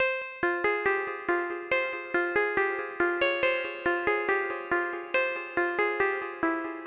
MB - Loop 1 - 70BPM.wav